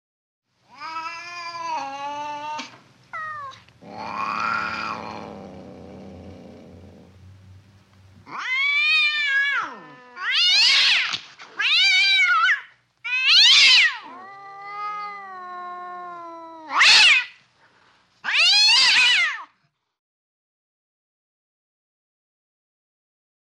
На этой странице собраны звуки кошачьих драк – от яростного шипения до громких воплей и топота лап.
Рычание и фырканье двух агрессивных кошек